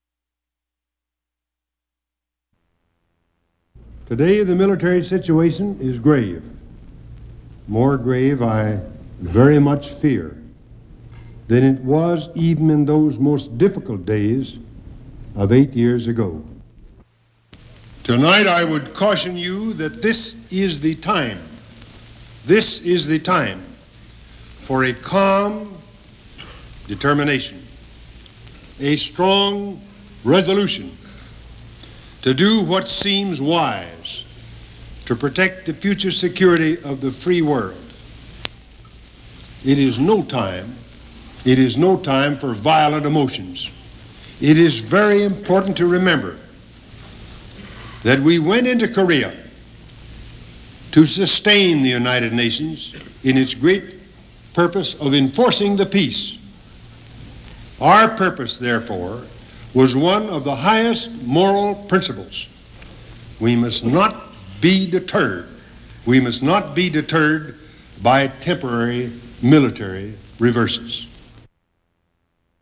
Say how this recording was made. Broadcast 1950.